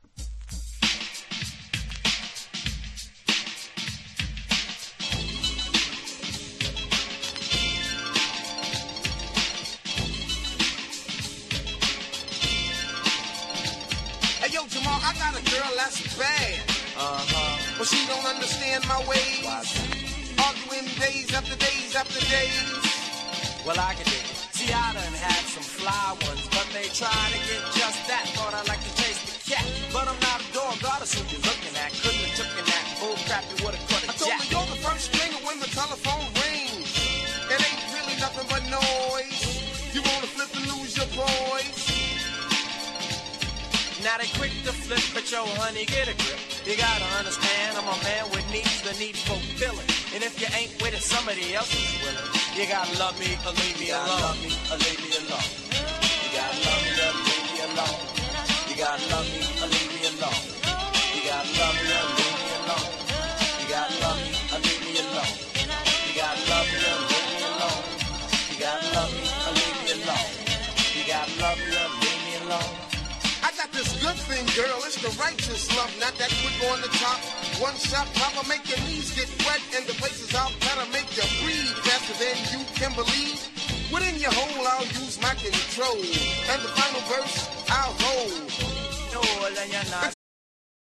1. HIP HOP CLASSICS >